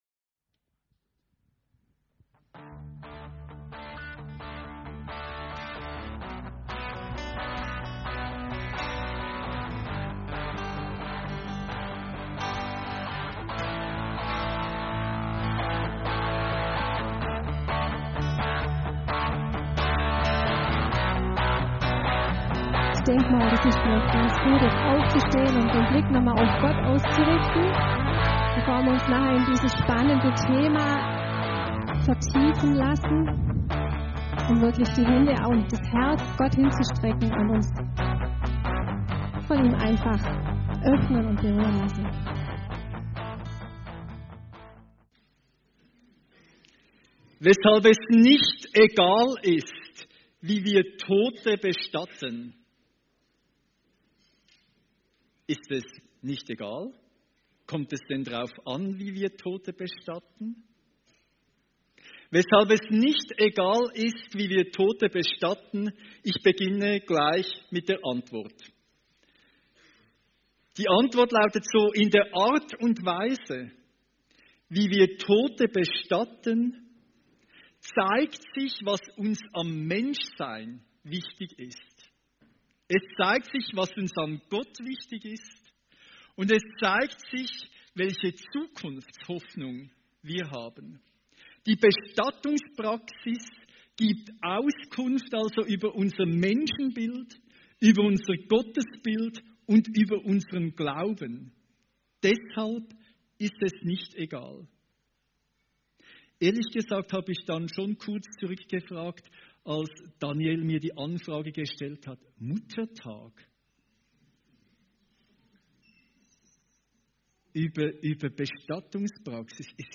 Predigt als Audio